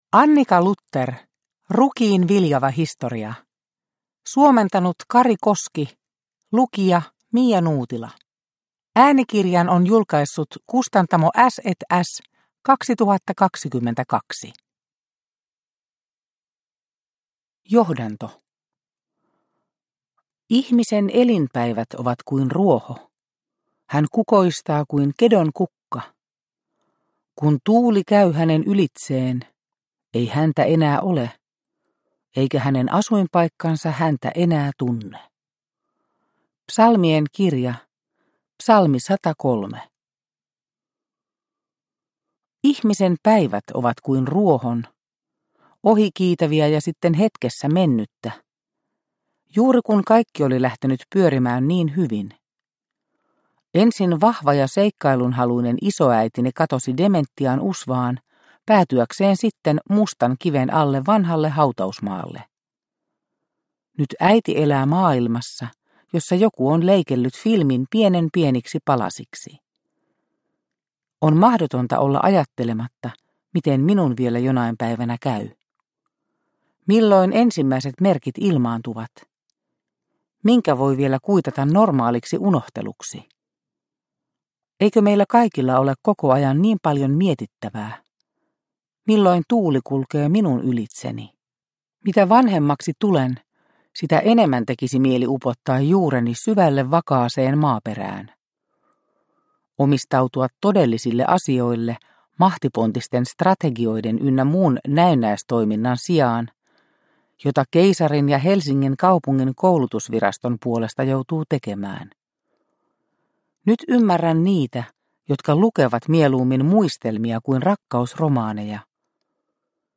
Rukiin viljava historia – Ljudbok – Laddas ner